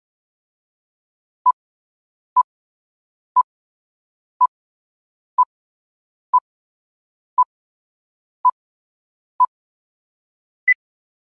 Green screen countdown hitung mundur.
Efek Countdown atau hitung mundur.